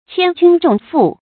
千鈞重負 注音： ㄑㄧㄢ ㄐㄩㄣ ㄓㄨㄙˋ ㄈㄨˋ 讀音讀法： 意思解釋： 鈞：古代的重量單位，合三十斤。